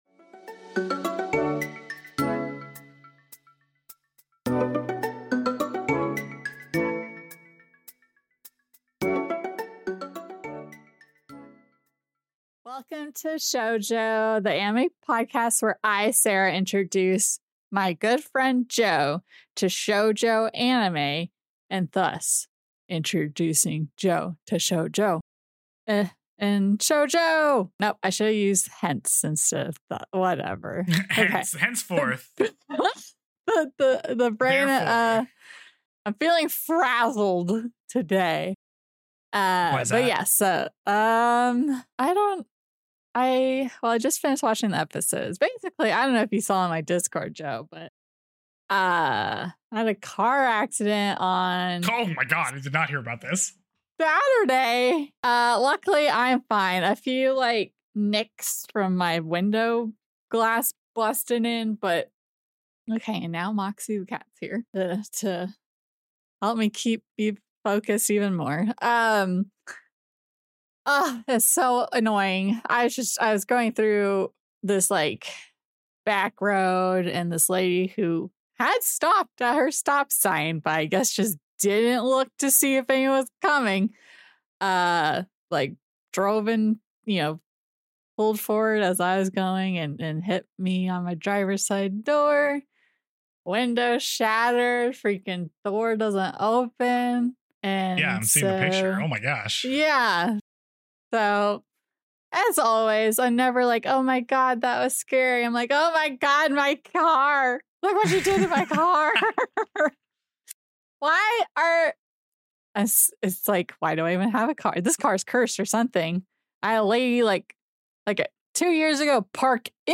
Audio-only version of the YouTube video.